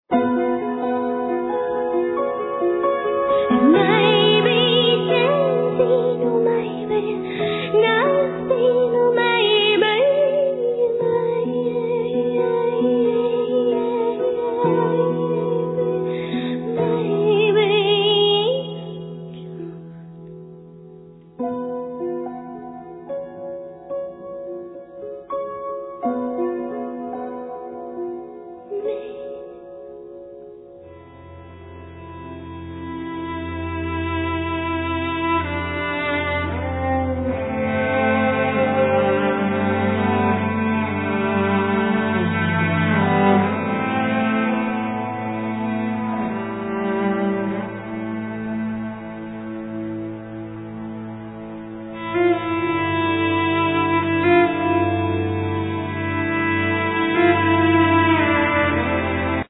Piano, Vocals
Drums
Cello
Elctric guitar
Guitar, Bass